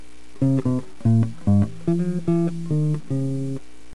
caminandoespinas_bass.mp3